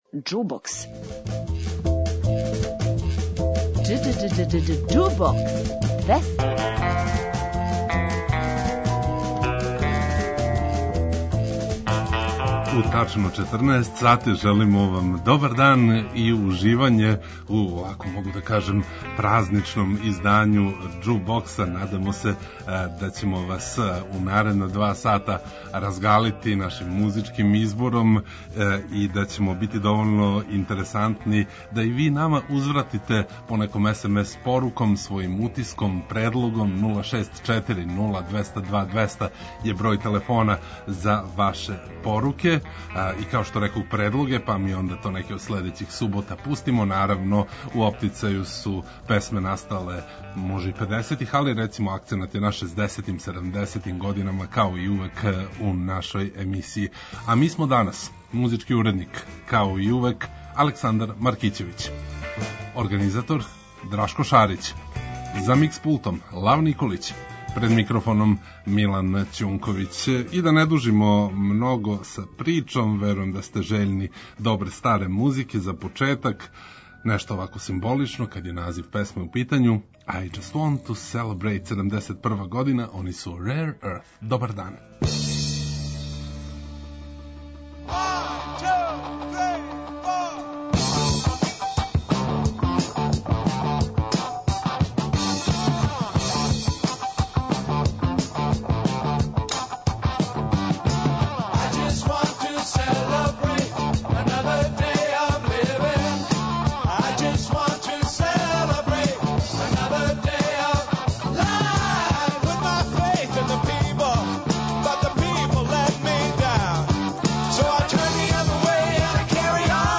преузми : 27.89 MB Џубокс 202 Autor: Београд 202 Уживајте у пажљиво одабраној старој, страној и домаћој музици.